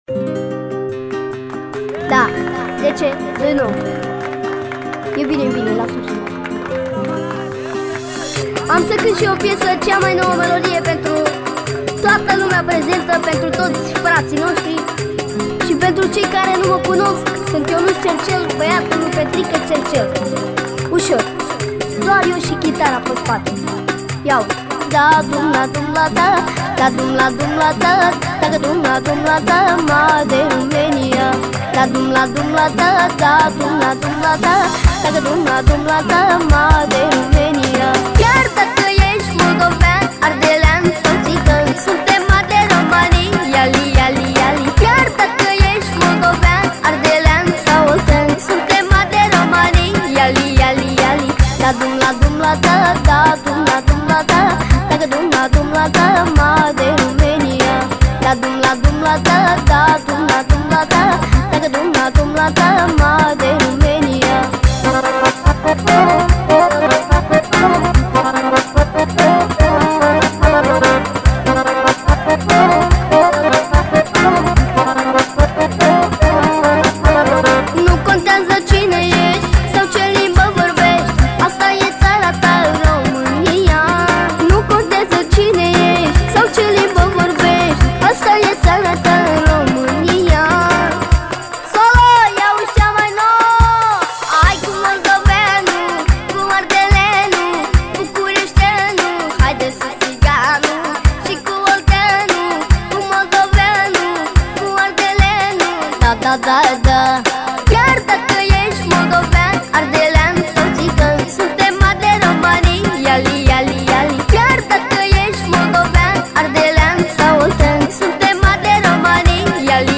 элементы поп-музыки, танцевальной музыки и манеле